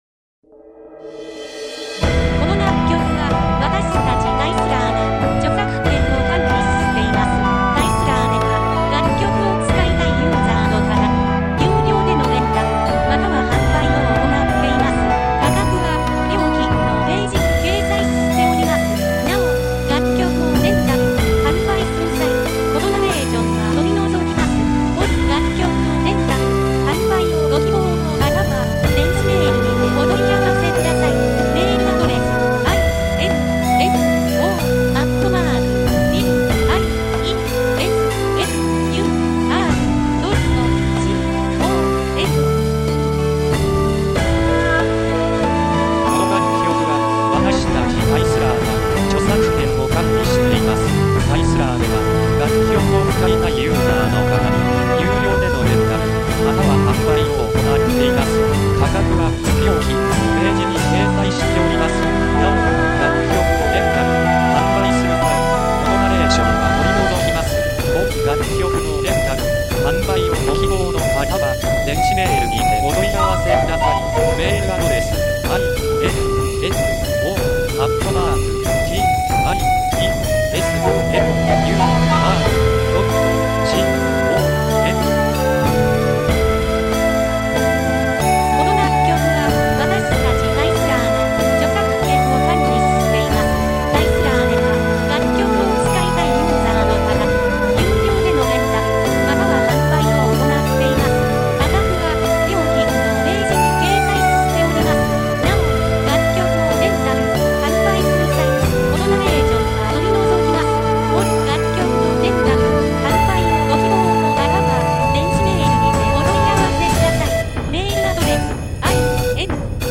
なお、楽曲をレンタル・販売する際、バックのナレーション等は取り除きます。